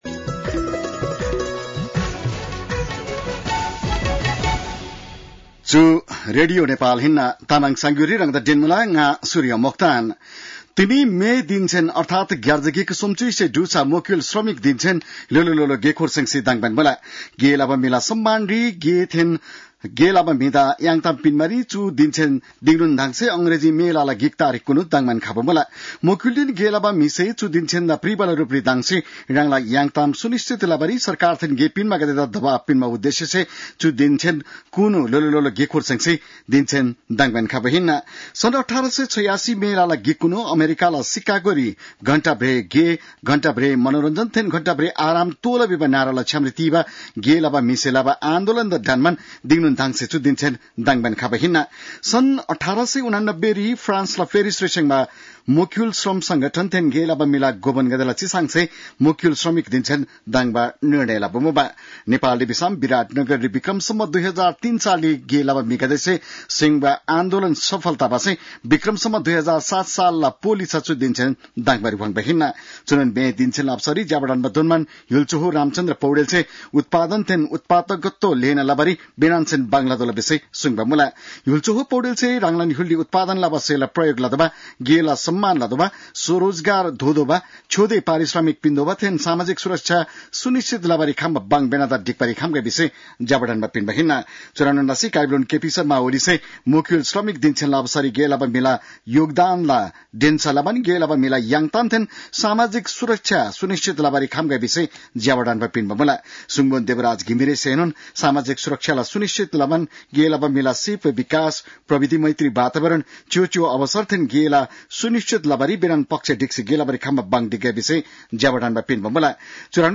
तामाङ भाषाको समाचार : १८ वैशाख , २०८२